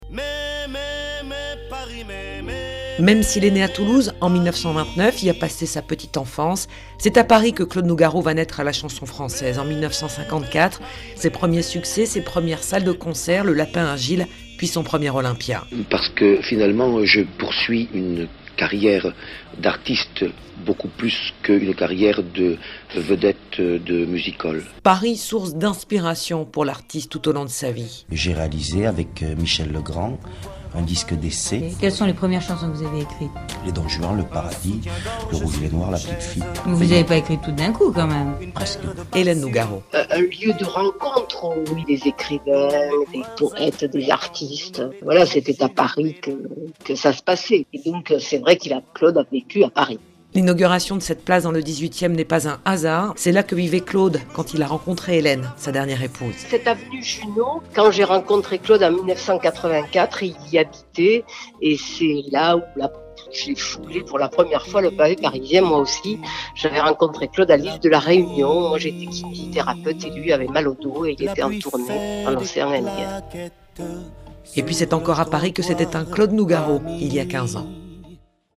Reportage Sud Radio de